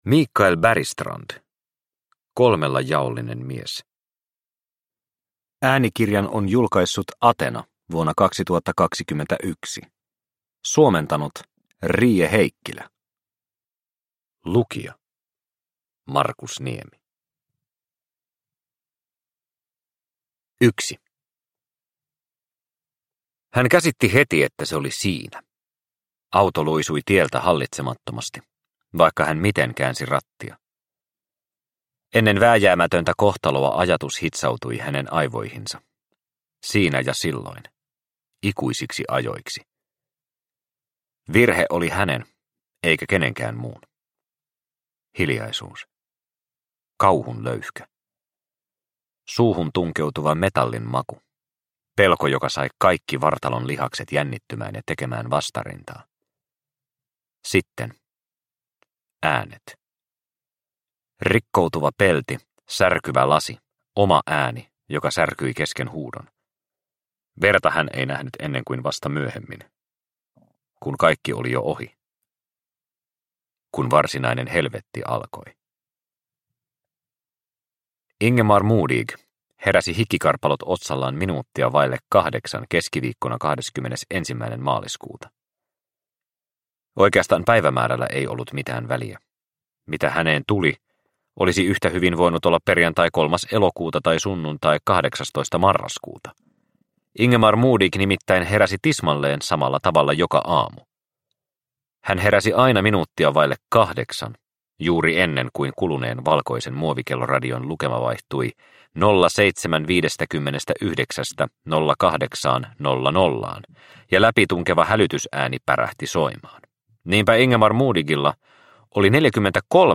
Kolmella jaollinen mies – Ljudbok – Laddas ner